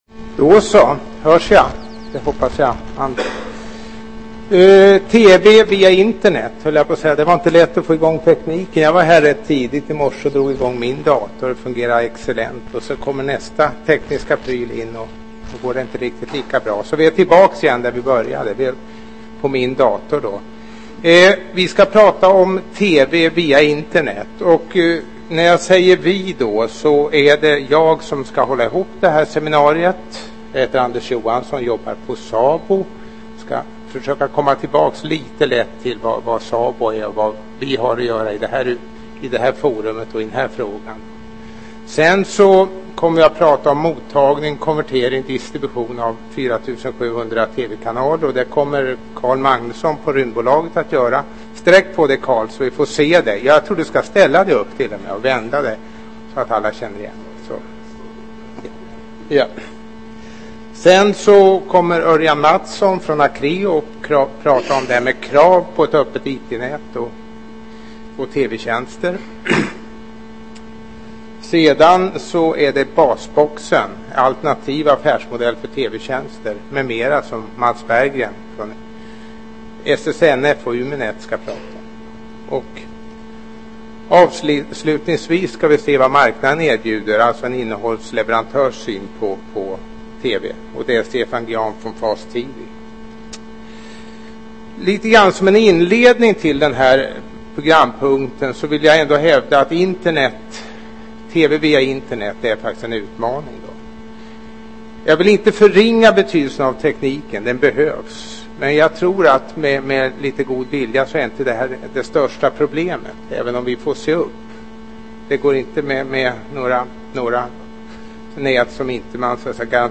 Internetdagarna 2006 - Internet - Visioner och möjligheter
Seminariet belyser de viktiga fr�gorna som beh�ver l�sas f�r att n� framg�ng med TV via Internet.